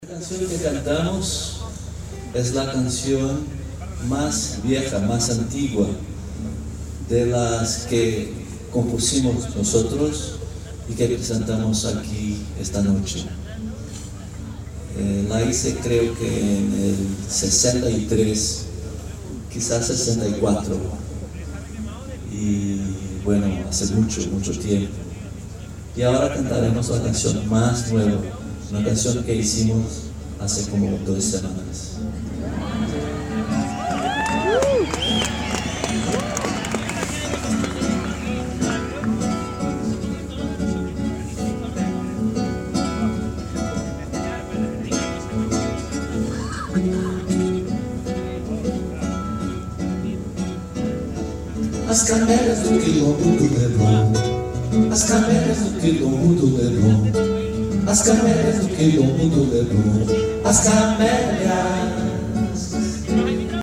Emotivo concierto